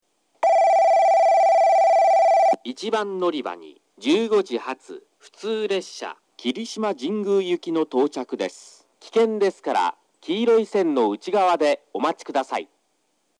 放送はJACROS簡易詳細型で、接近ベルが鳴ります。
スピーカーはFPS平面波です。なお放送の音割れが激しいですがこれは元からで、夜間音量の方が綺麗に聞こえます。
1番のりば接近放送（霧島神宮行き）→臨時列車です。